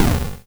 bakuhatu57.wav